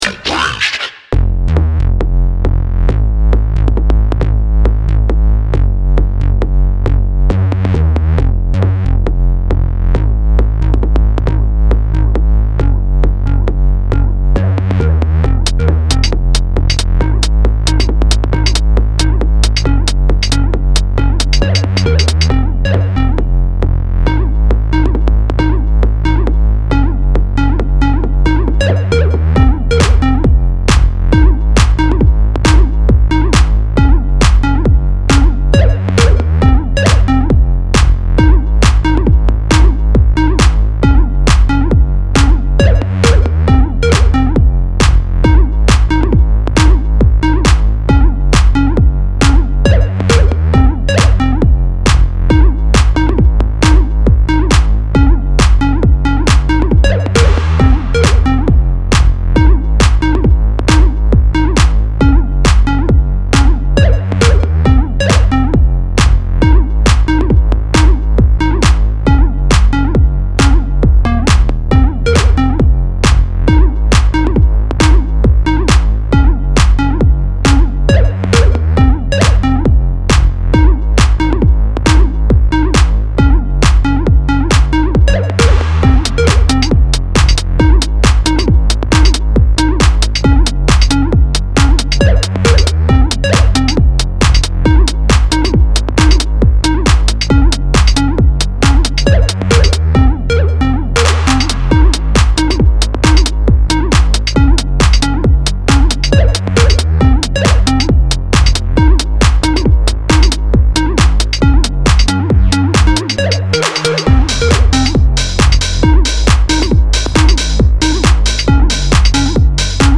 レイヴィーなセットに起用すべき一枚です。